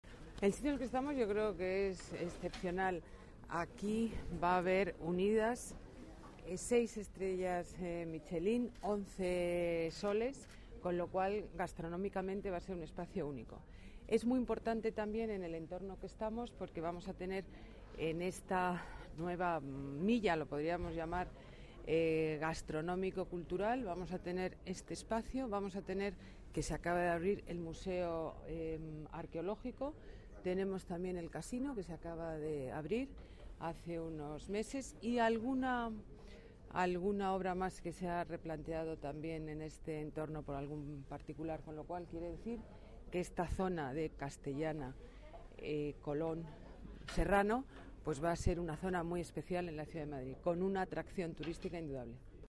Hoy la alcaldesa Ana Botella ha visitado las obras de Platea Madrid dedicado a todos los paladares doce horas al día
Nueva ventana:Declaraciones de la alcaldesa de Madrid, Ana Botella